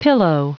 Prononciation du mot pillow en anglais (fichier audio)
Prononciation du mot : pillow